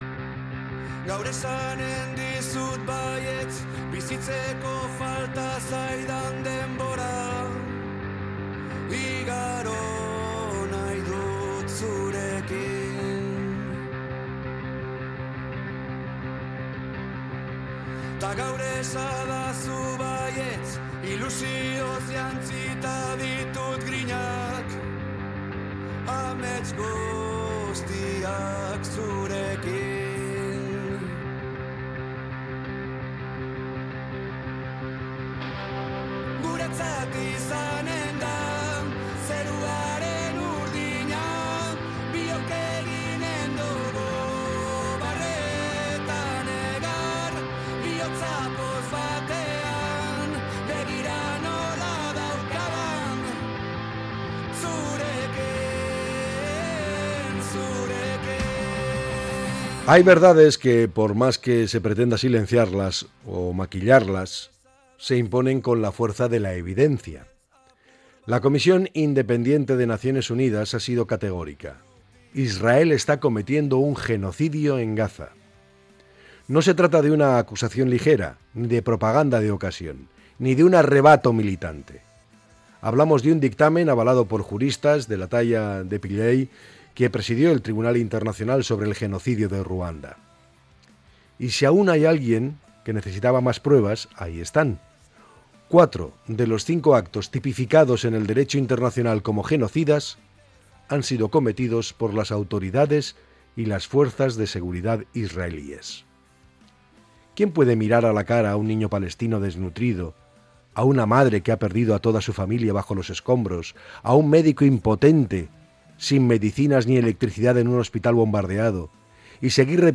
El comentario
Podcast Opinión